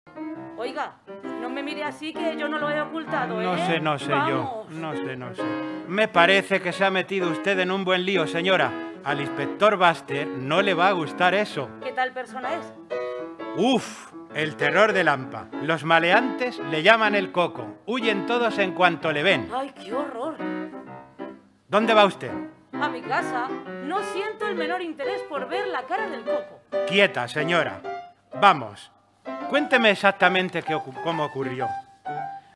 Segundo premio: 1.000 euros al grupo, diploma y publicación en la Biblioteca Digital de la ONCE al trabajo presentado por ‘Alfateatro’, que interpreta la obra